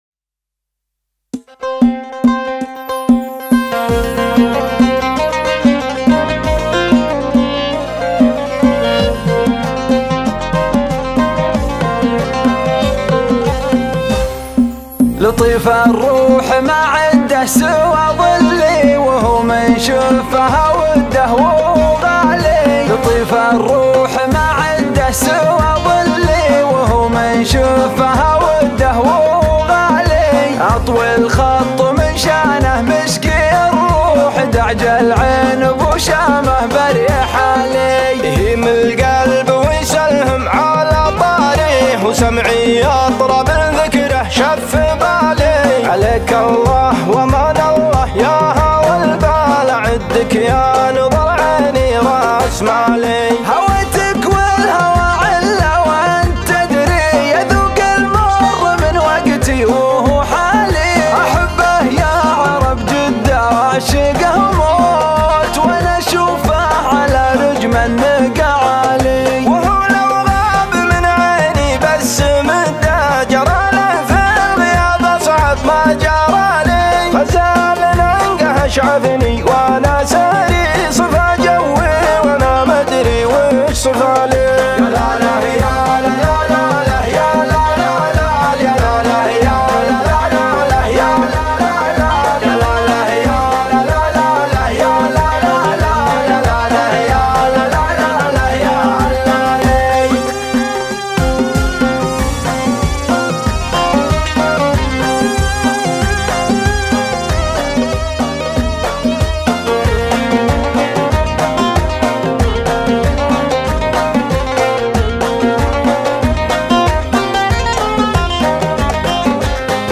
شيلات دويتو